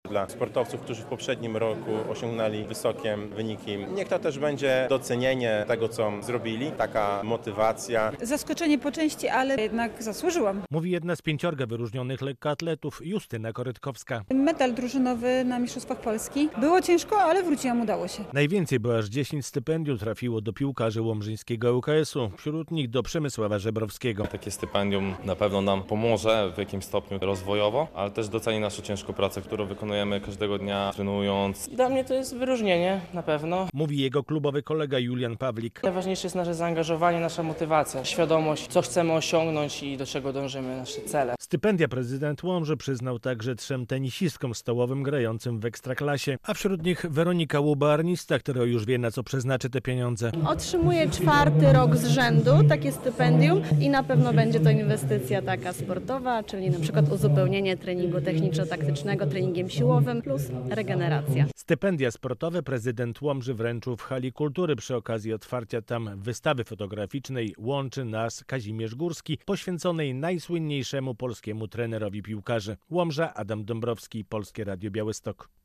relacja
- To forma docenienia ich osiągnięć w ubiegłym roku i motywacja do dalszej pracy. Kryterium były przede wszystkim wyniki sportowe - mówił prezydent miasta Mariusz Chrzanowski.
Mariusz Chrzanowski uhonorował łomżyńskich sportowców w Hali Kultury, gdzie otwarto właśnie wystawę fotograficzna „100 rocznica urodzin Kazimierza Górskiego, Trenera Tysiąclecia”.